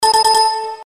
без слов
короткие
электронные
Системный звук ошибки Windows